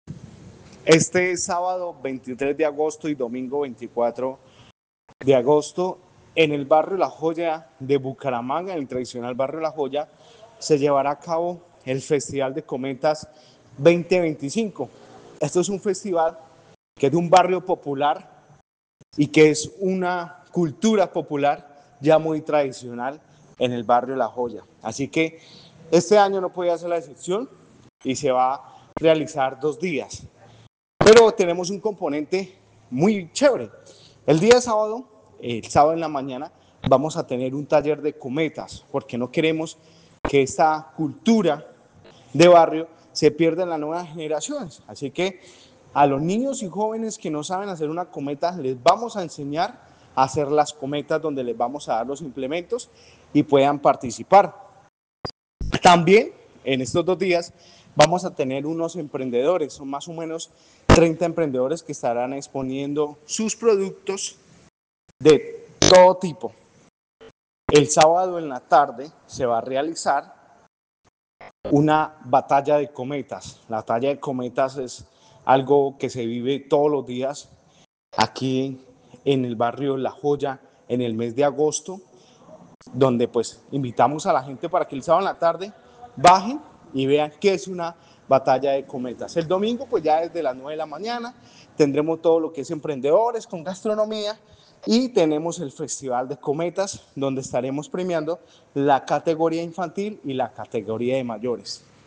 Larry Sánchez, edil comuna 5 de Bucaramanga